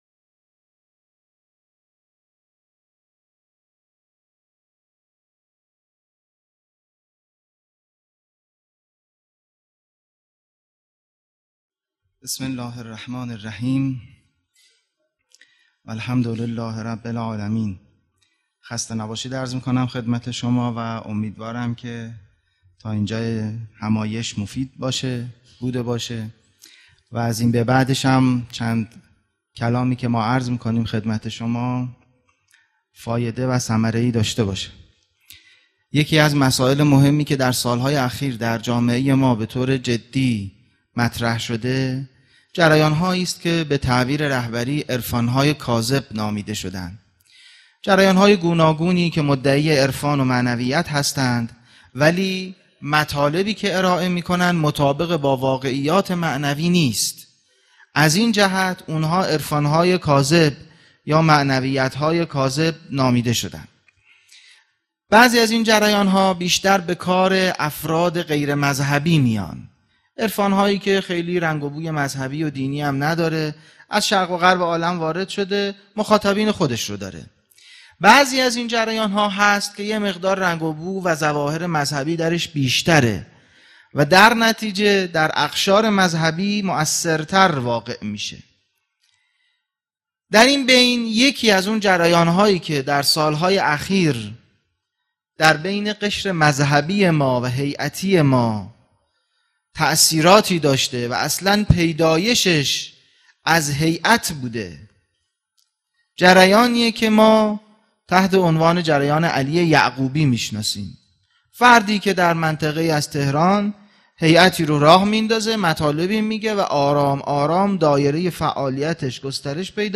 سخنرانی
هشتمین همایش هیأت‌های محوری و برگزیده کشور | شهر مقدس قم - مسجد مقدس جمکران